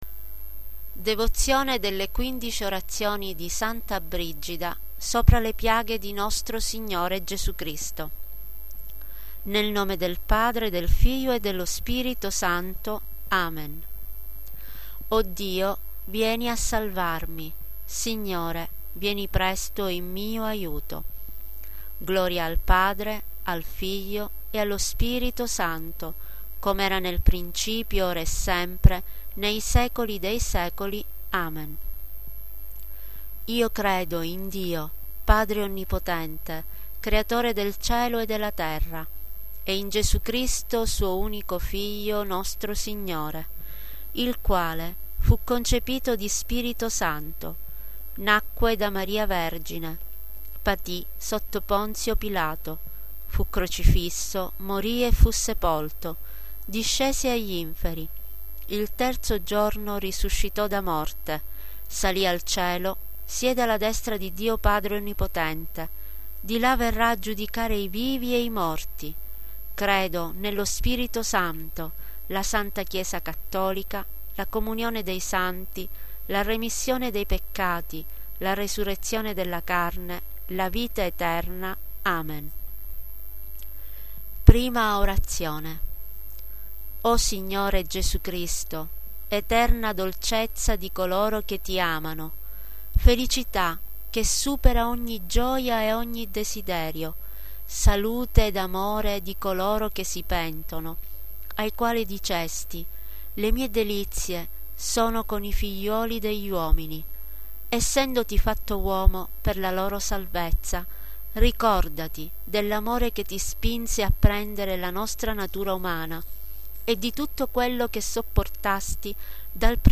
Le 15 Orazioni di S. Brigida, con voce guida mp3, da recitarsi per un anno, ...